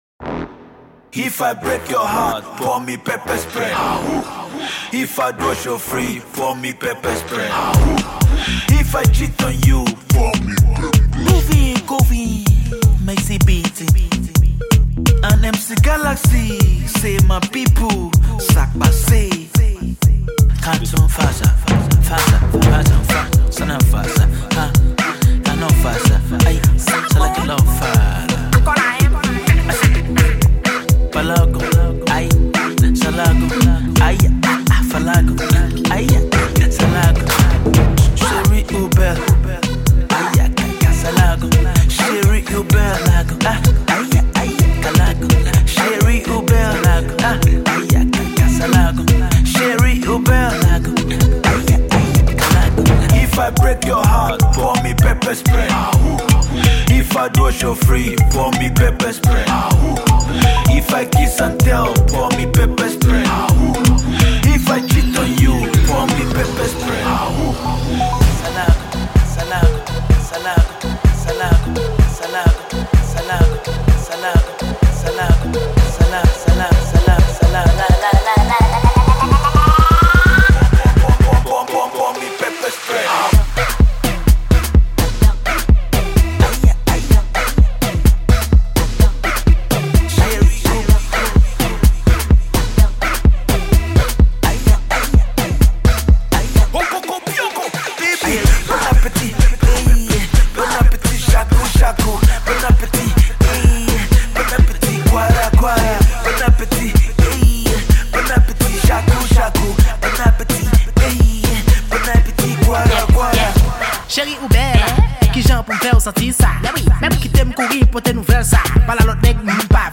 a dance song all the way